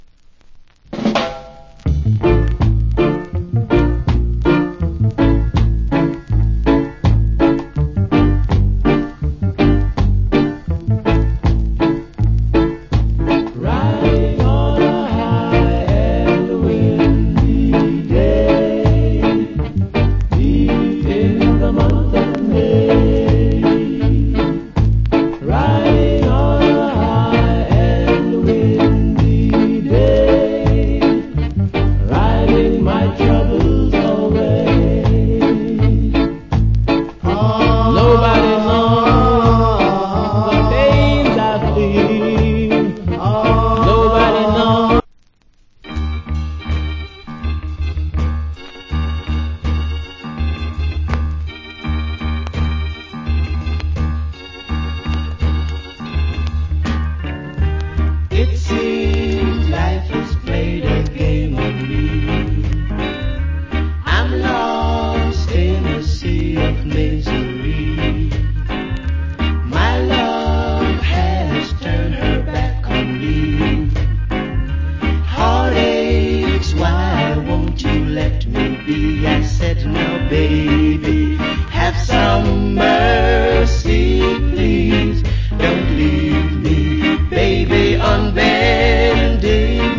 Wicked Rock Steady Vocal.